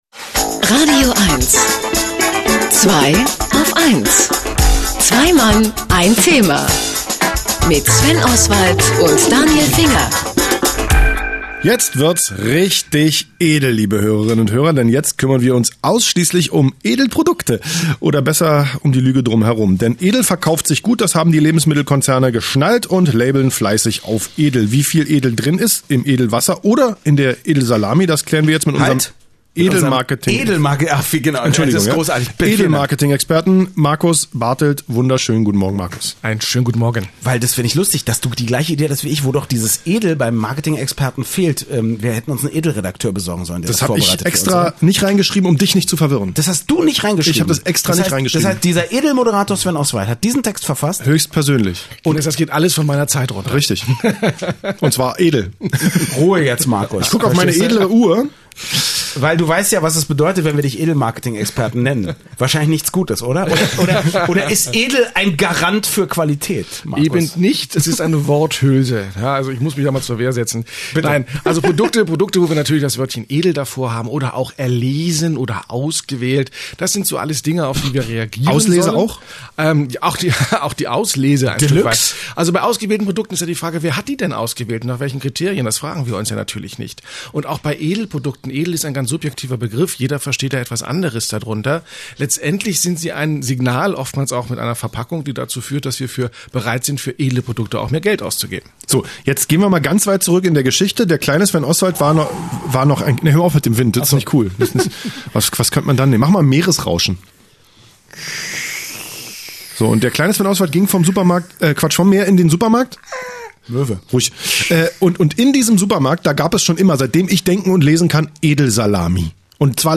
Nein, es ging nicht um Hamlet, sondern um den Begriff „edel“ in der Werbung, als ich am heutigen Sonntag zu Gast bei „Zweiaufeins“ im „radioeins„-Studio war.